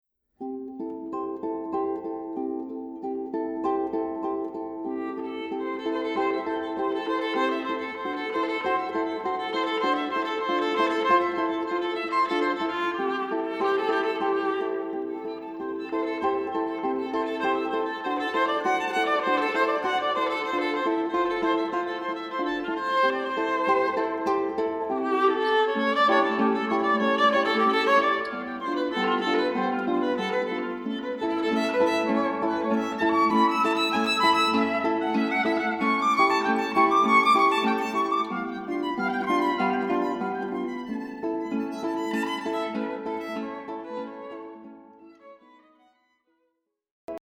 recent works for violin and guitar
The balance is very pleasing.
Classical